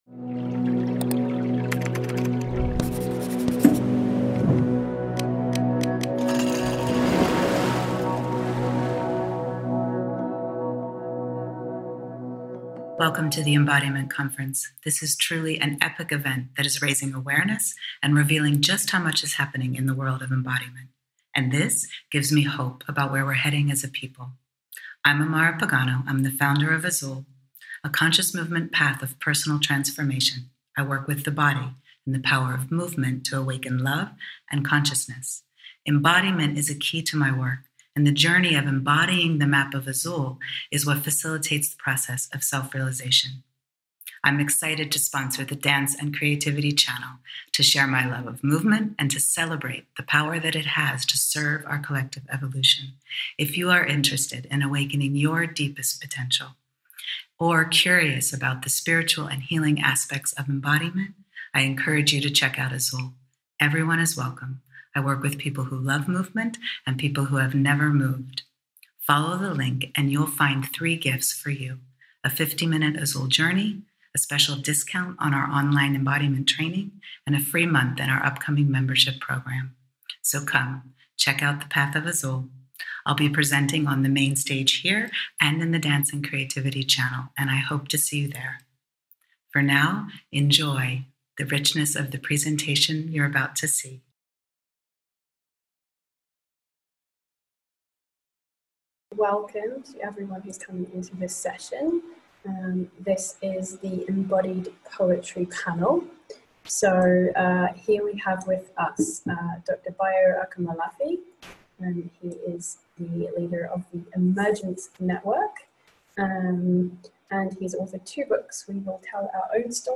PANEL: Embodied Poetry Panel Beginning or no understanding of topic, Open to all Movement not required Likely soothing Please join us for this heartening, lively conversation in which five poets from around the globe will weave diverse perspectives on creative process, share their poetry live, and invite you into your innate inspiration and poetic powers of perception. Poetry can be medicine for sickness in our psyches and it can also be a wake up call when our eyes glaze over and we forget to be curious.